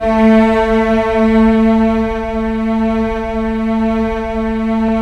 Index of /90_sSampleCDs/Roland LCDP13 String Sections/STR_Symphonic/STR_Symph.+attak